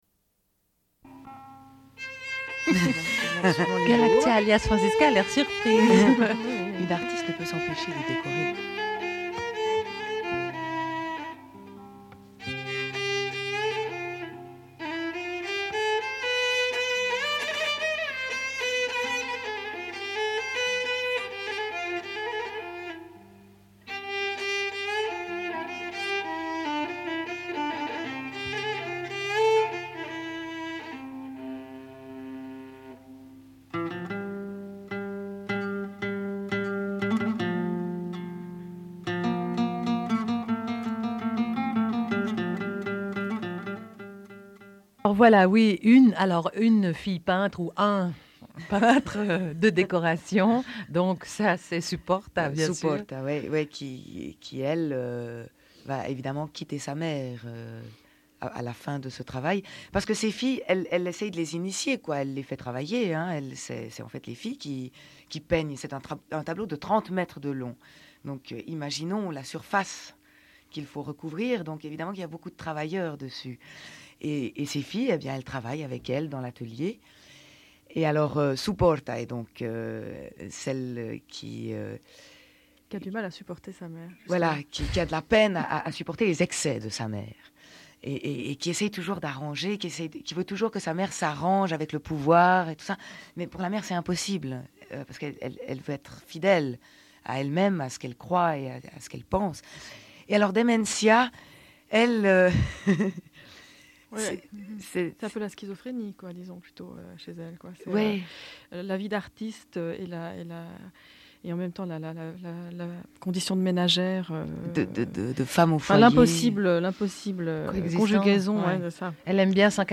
Entretien en direct
Une cassette audio, face B